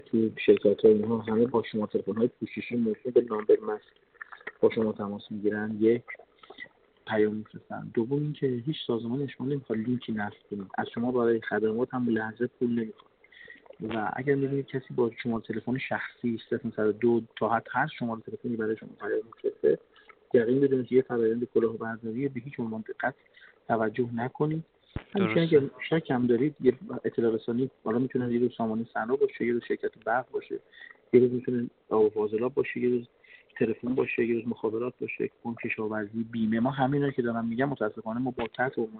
در همین راستا سرهنگ رامین پاشایی، معاون فرهنگی و اجتماعی پلیس فتا در گفت‌وگو با ایکنا نسبت به کلاهبرداری از شهروندان در پوشش پیامک جعلی سامانه ثنا هشدار داد و اظهار کرد: متأسفانه شاهد هستیم که کلاهبرداران سایبری از سامانه‌های دولتی مانند سامانه ثنا سوءاستفاده می‌کنند و برای برخی از شهروندان مزاحمت ایجاد کرده‌اند.